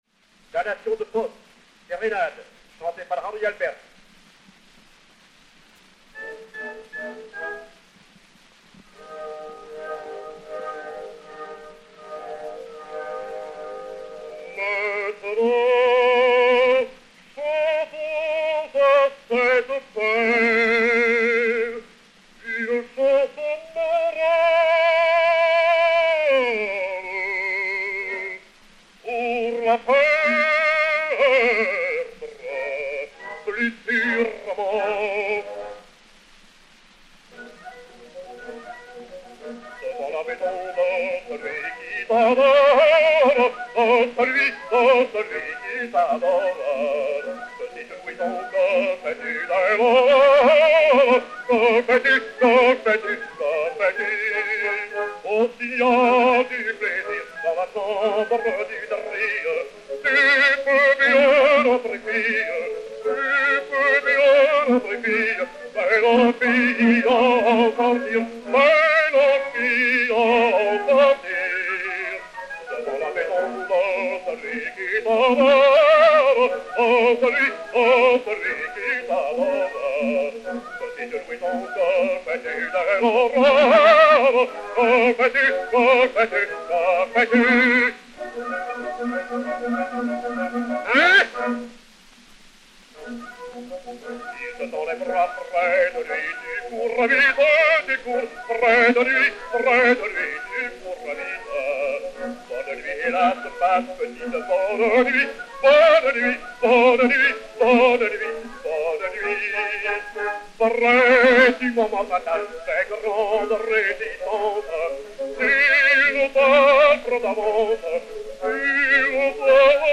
Henri Albers (Méphistophélès) et Orchestre